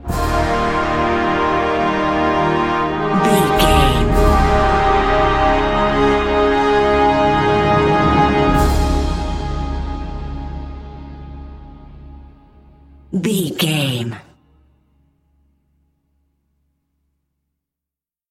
Aeolian/Minor
Slow
strings
drums
orchestral hybrid
dubstep
energetic
intense
bass
synth effects
heroic
driving drum beat
epic